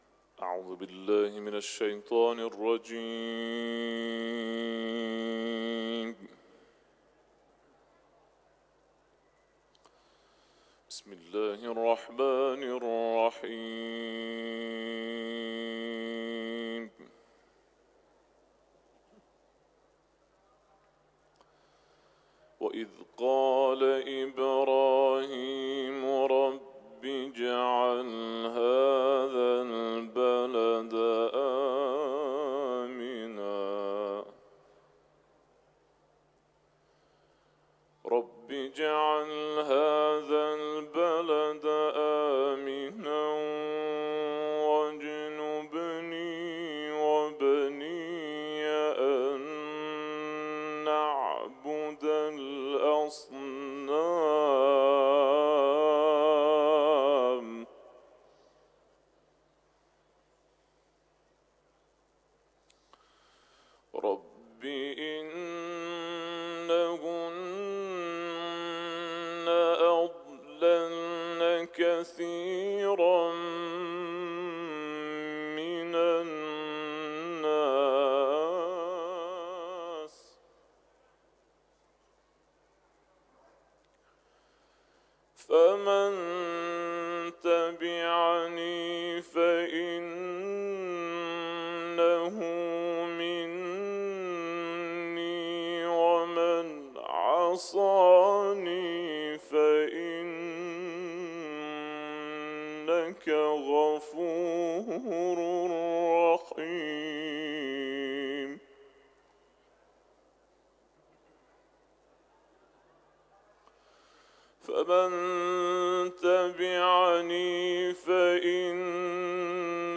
حرم مطهر رضوی ، تلاوت سوره ابراهیم